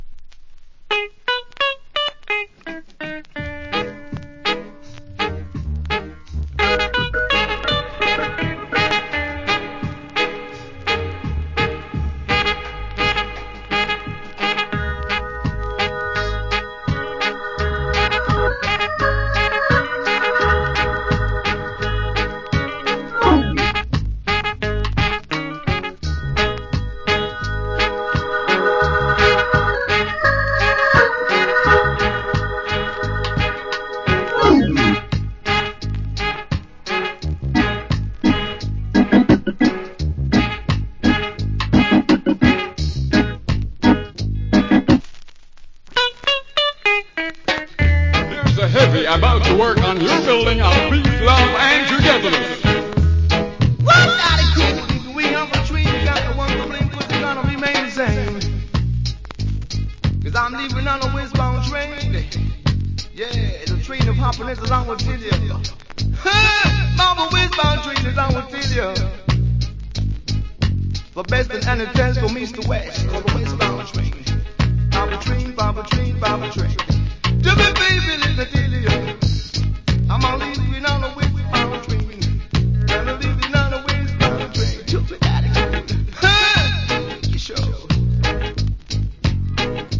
Killer Organ Inst.